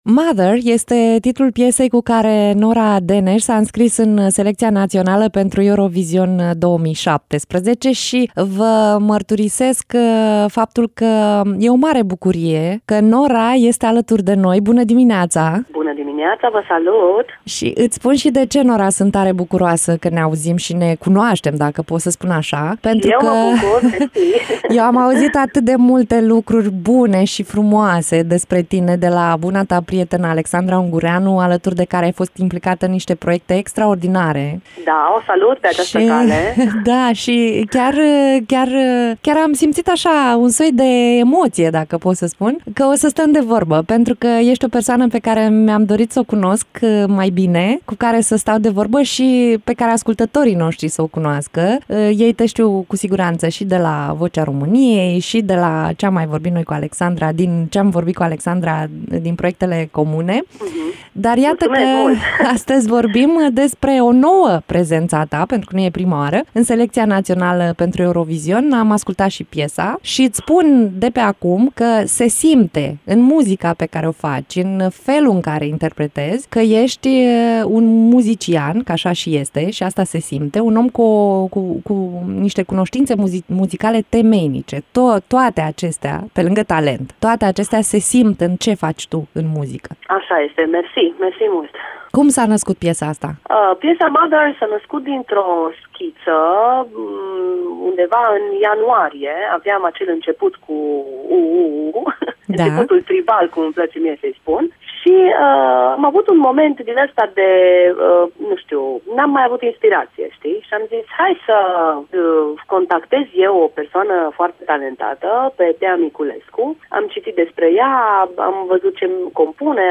Radio Iaşi – Cel mai ascultat radio regional - știri, muzică și evenimente